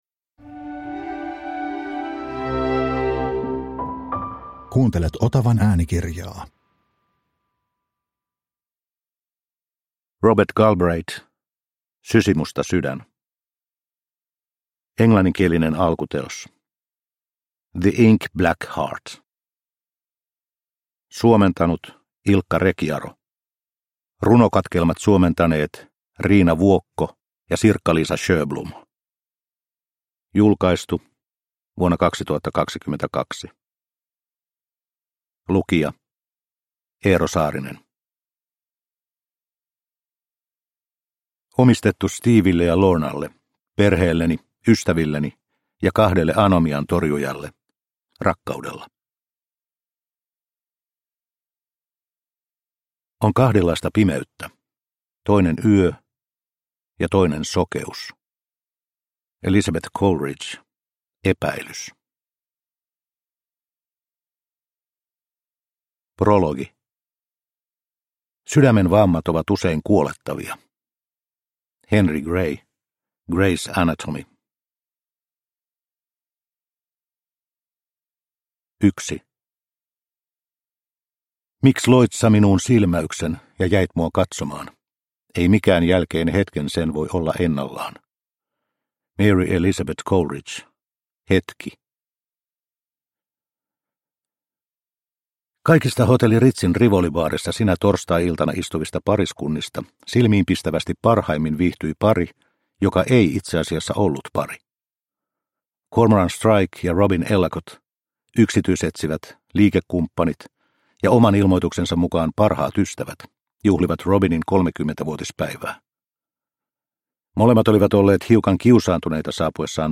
Sysimusta sydän – Ljudbok – Laddas ner